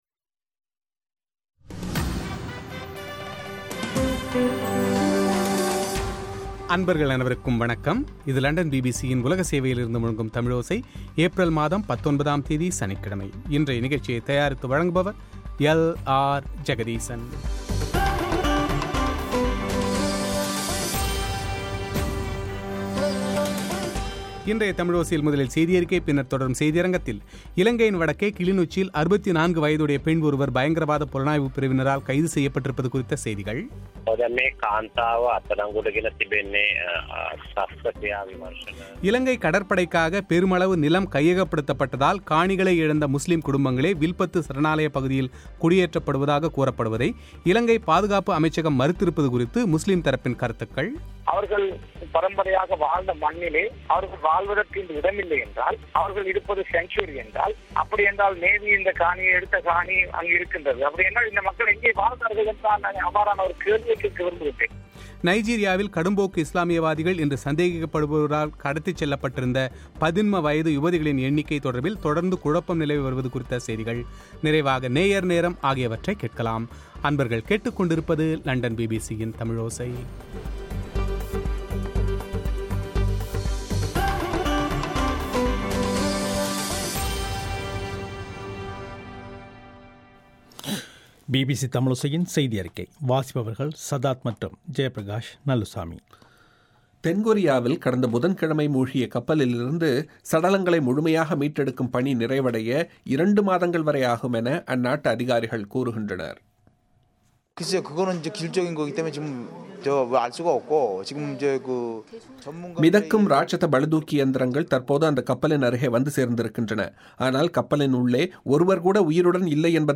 அமைச்சர் ரிஷாத் பதியுதீனின் செவ்வி